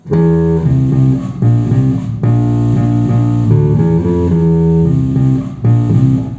bludicka_basa_refren.mp3